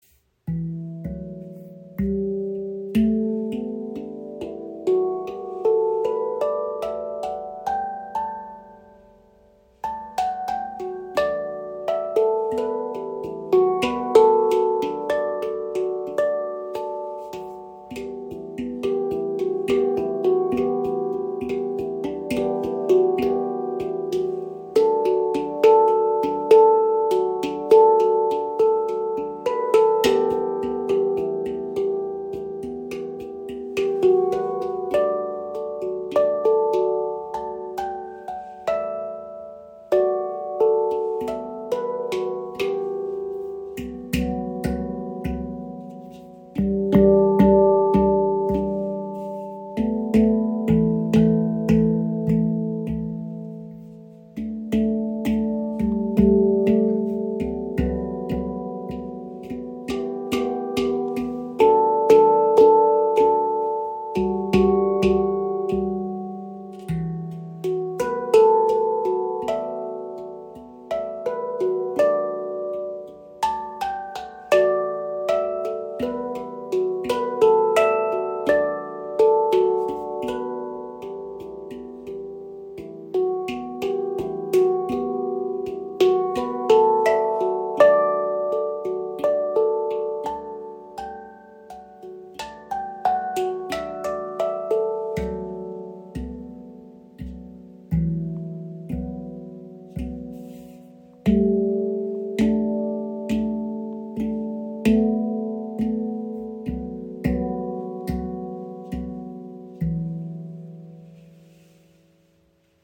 • Icon Edelstahl-Handpan – warmer, langer Sustain für meditative Musik
Handgefertigte Edelstahl-Handpan mit offener, ruhiger Stimmung, tiefer Erdung, langem Sustain und fliessendem Klang – ideal für Meditation und Klangreisen.
Die E-Amara-Stimmung entfaltet eine ruhige, offene Klanglandschaft mit tiefer Erdung und sanfter Weite.
Der Charakter bleibt ruhig, tragend und ausgewogen – weder melancholisch noch dominant.
Töne: E – (F# G A) B D E F# G A B D E F# G A